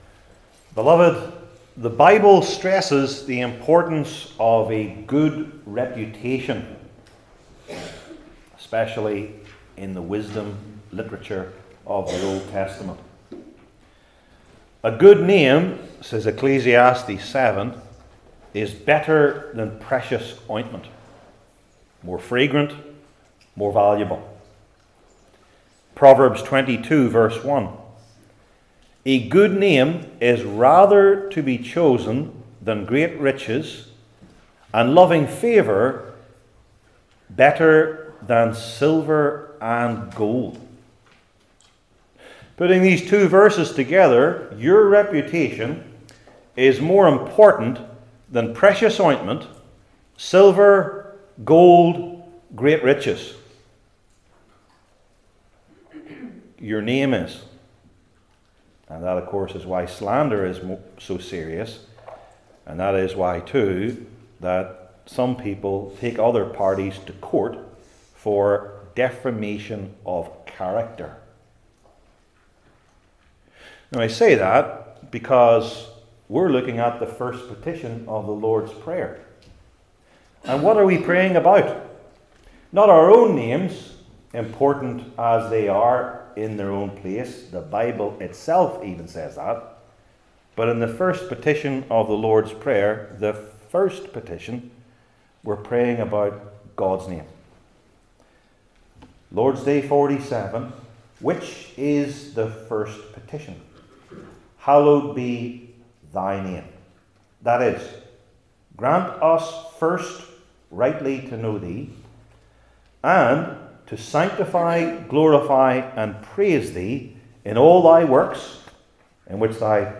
Malachi 1 Service Type: Heidelberg Catechism Sermons I. For Divine Knowledge II.